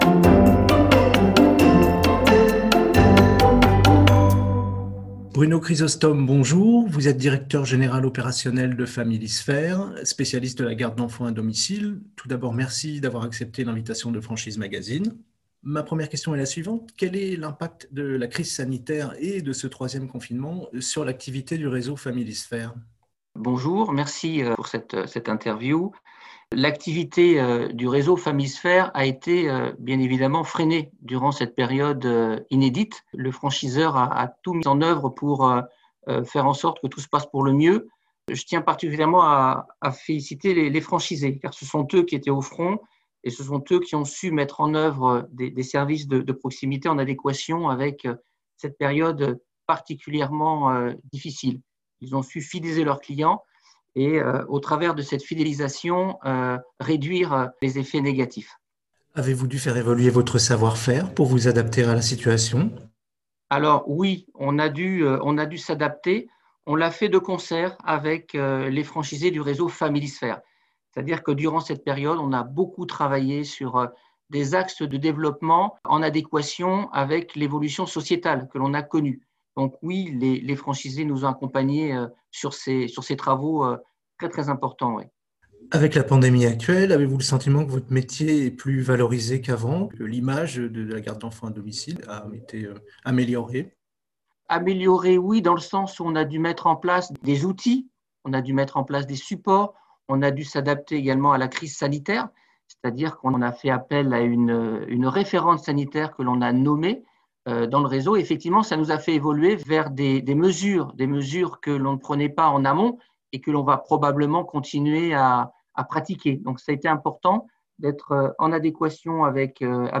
Au micro du podcast Franchise Magazine : la Franchise Family Sphere - Écoutez l'interview